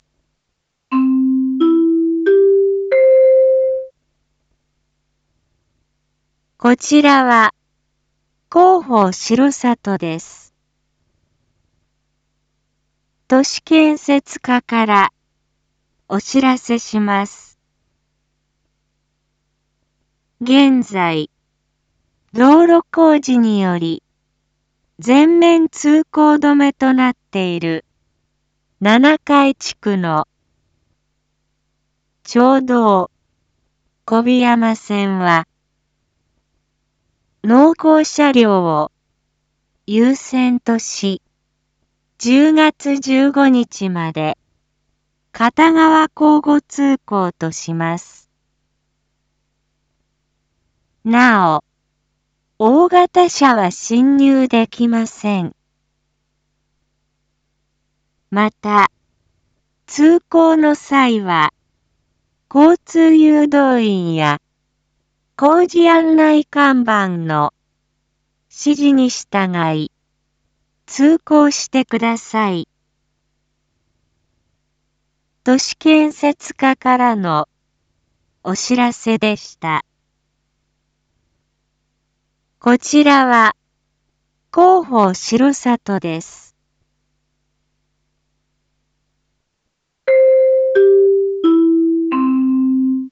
一般放送情報
Back Home 一般放送情報 音声放送 再生 一般放送情報 登録日時：2023-10-12 19:01:34 タイトル：町道４号線の片側車線一時開放について インフォメーション：こちらは、広報しろさとです。